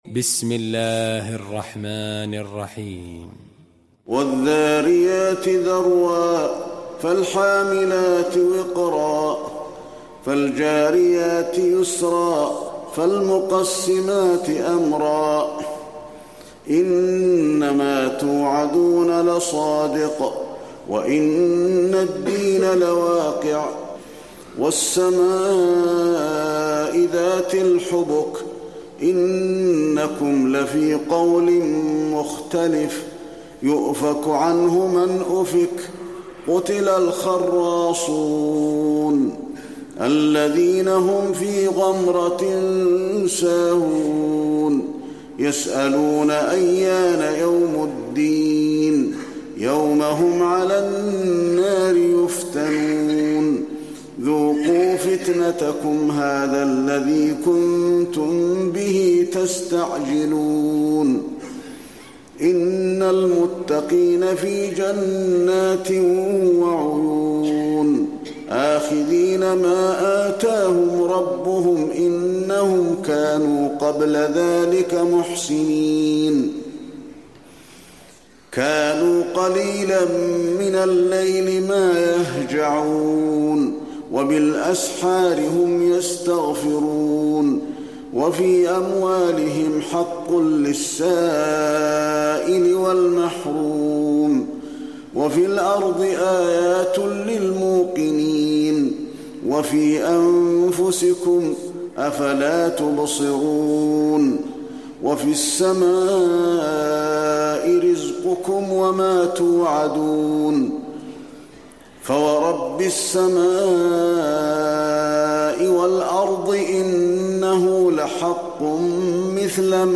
المكان: المسجد النبوي الذاريات